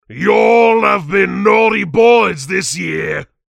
( Saxton Hale responses )
Licensing This is an audio clip from the game Team Fortress 2 .